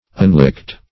Unlicked \Un*licked"\, a.